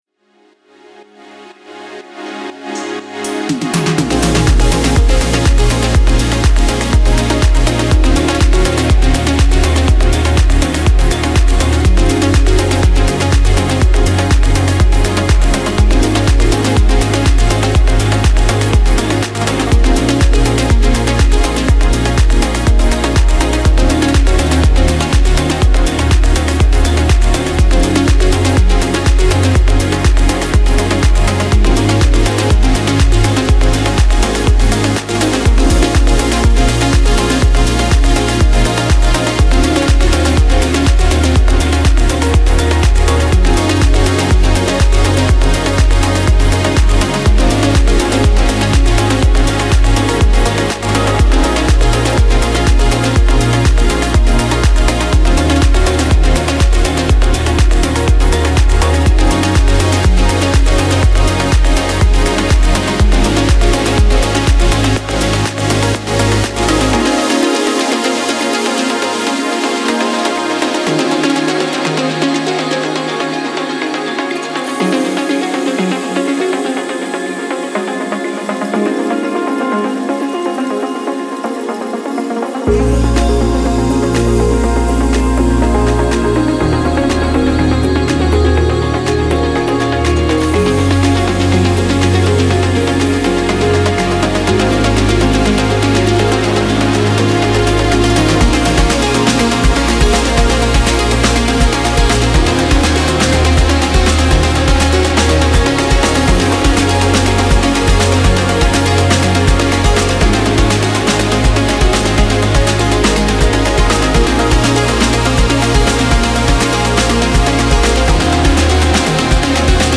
پر‌انرژی , تدوین فیلم و عکس , رقص , موسیقی بی کلام
موسیقی بی کلام دنس